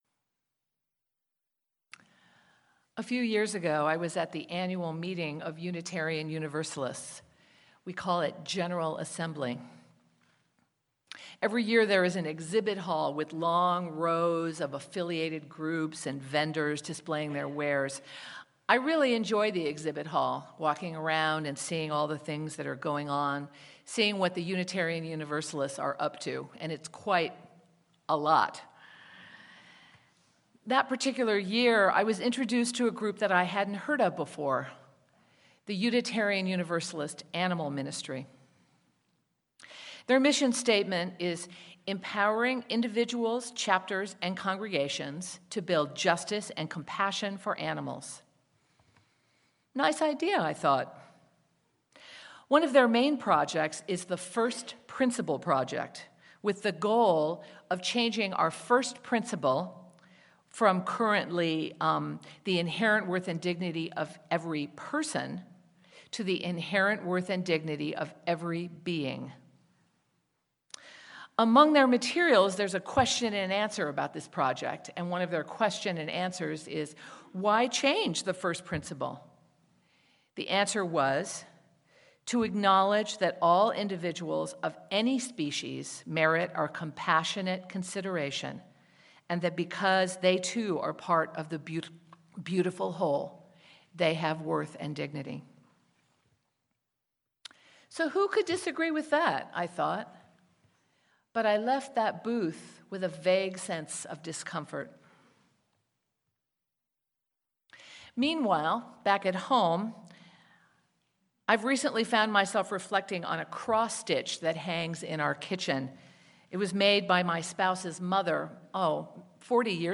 Sermon-Animals-and-Humans.mp3